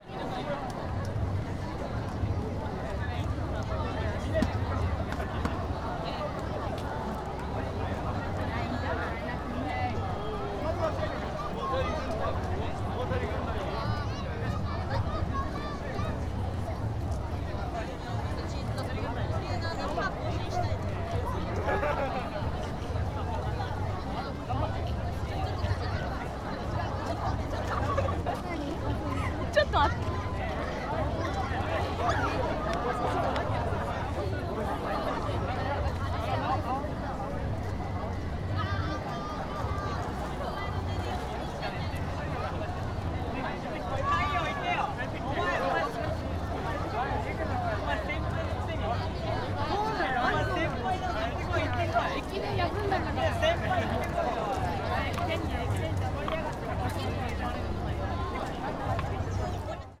Fukushima Soundscape: Machi-naka Park